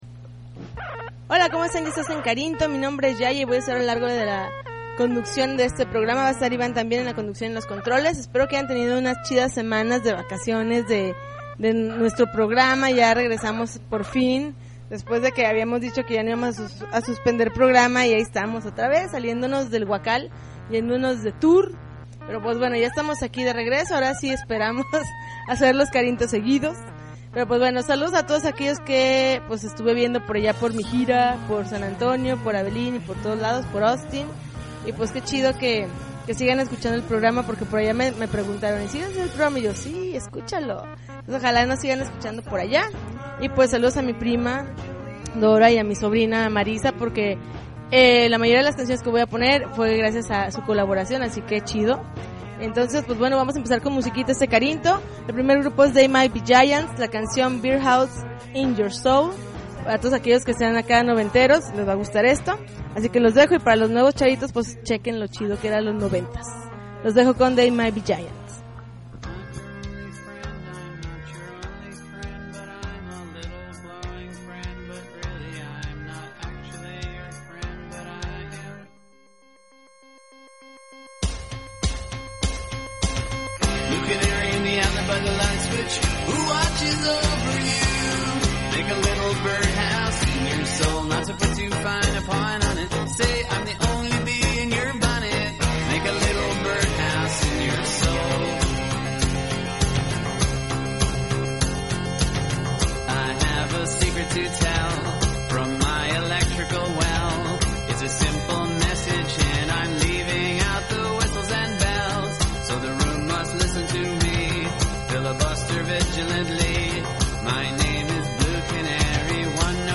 August 2, 2009Podcast, Punk Rock Alternativo